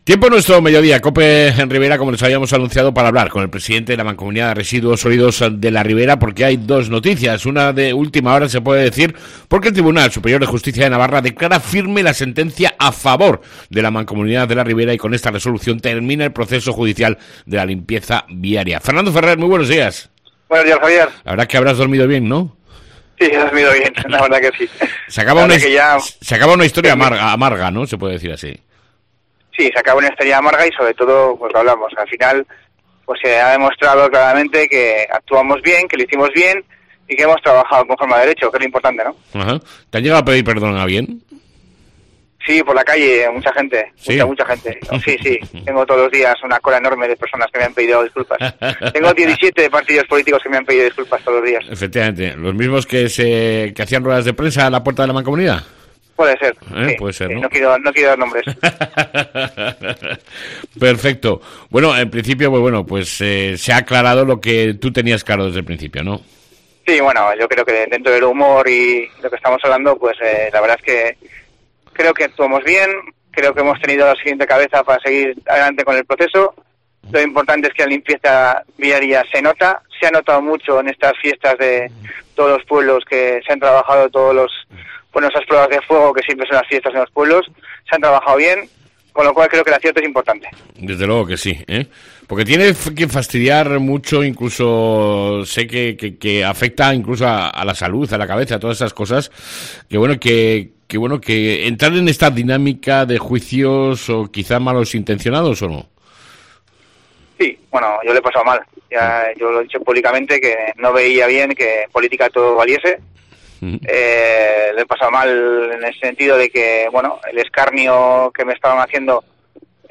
ENTREVISTA CON EL PRESIDENTE DE LA MANCOMUNIDAD, FERNANDO FERRER